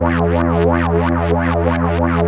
3100 AP  E 3.wav